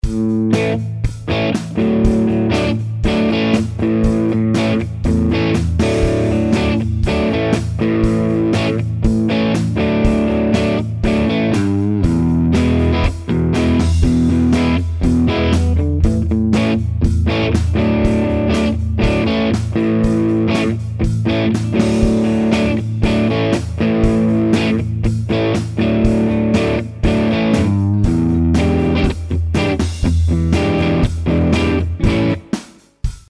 Seit ein paar Tagen zerrt er nicht mehr so richtig, daher vermute ich, dass eine Röhre dahin ist.
Daher habe ich nun ein paar Aufnahmen gemacht, in vier verschiedenen Zerrstufen.
Alle vier Aufnahmen sind pur von meiner HB CST24 in der Mittelstellung (also beide Humbucker), alle Regler ganz auf, dann durch den Laney und über's SM57 in eine Spur in Cubase aufgenommen, ohne EQ, Comp, lediglich im StereoOut ist ein Standard-Limiter und Volume am Amp so geregelt, dass keine Übersteuerung in Cubase ankommt, der GrooveAgent läuft mit, damit's 'n bischen mehr gruuft ;-).